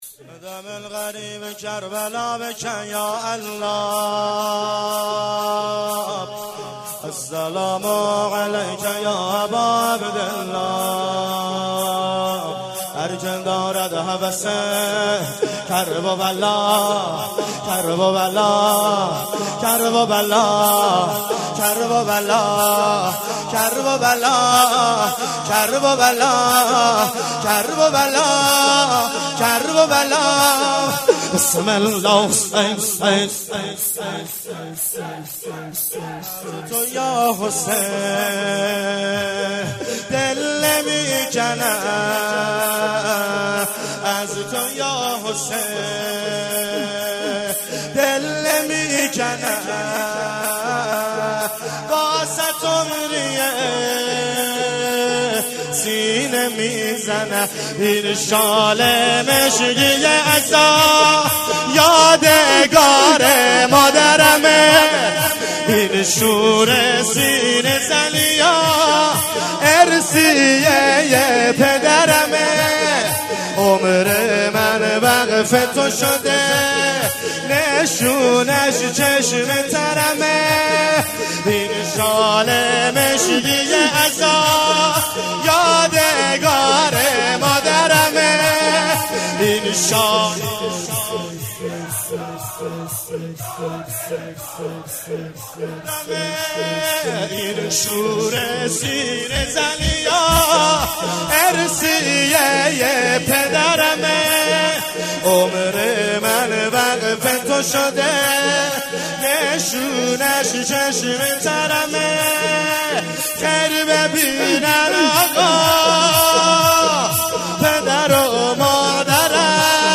شور4)شب شهادت امام حسن مجتبی(ع)
جلسه مذهبی بنت الحسین